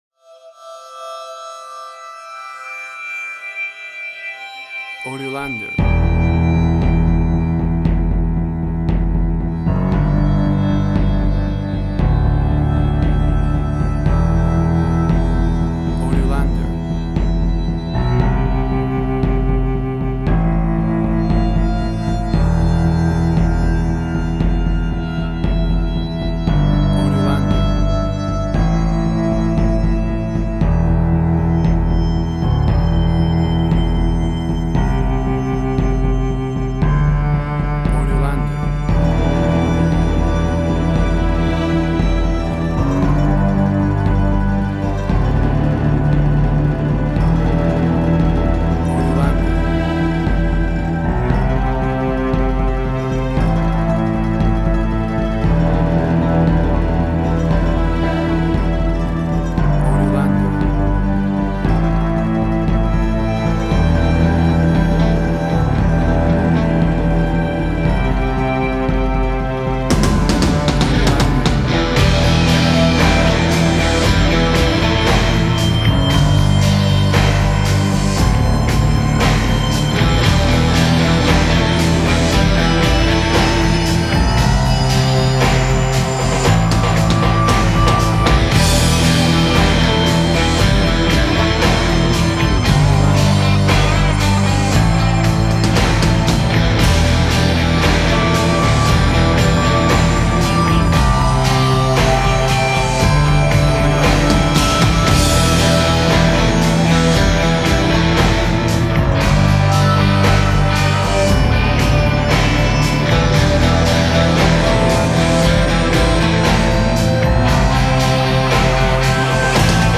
Post-Electronic.
Tempo (BPM): 58